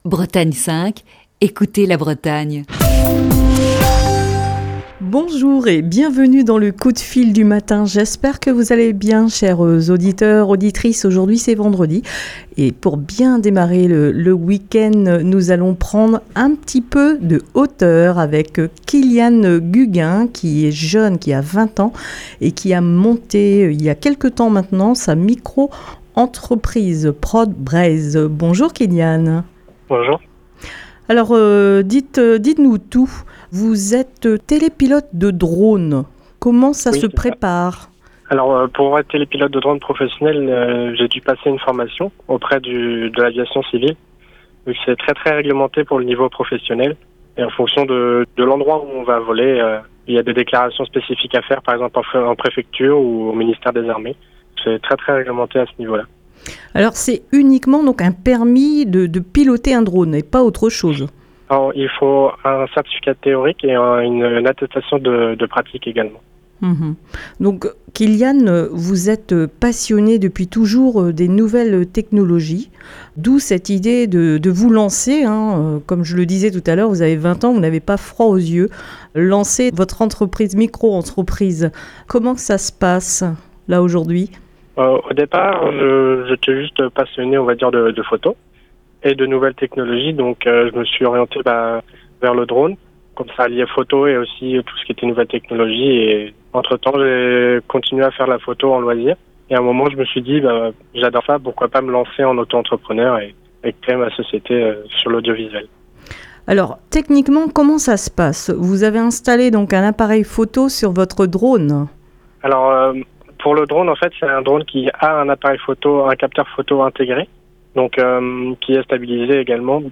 (Émission diffusée le 17 janvier 2020).